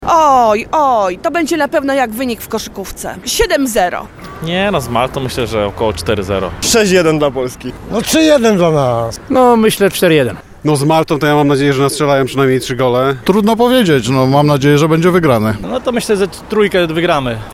Zapytaliśmy też kibiców jakim wynikiem zakończy się następny mecz naszej kadry